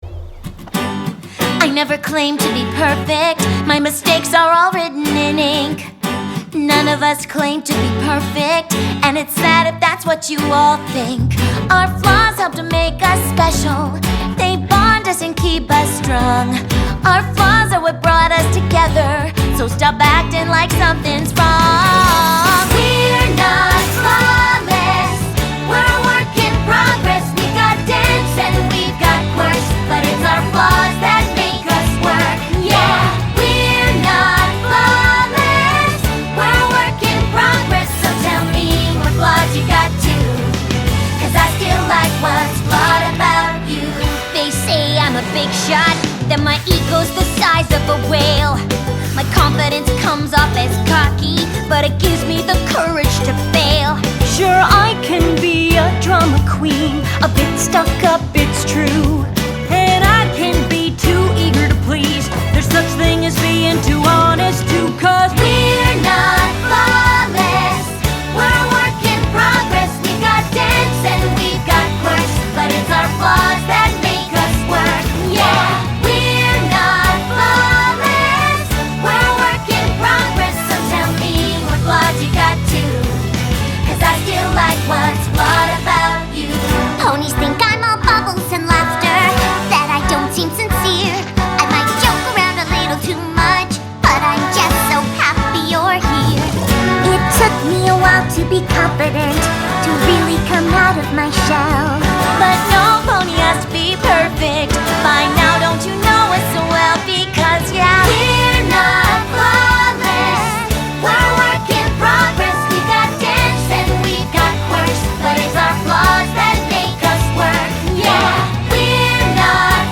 Звучит как ост к диснеевскому мультику.
Бодрит!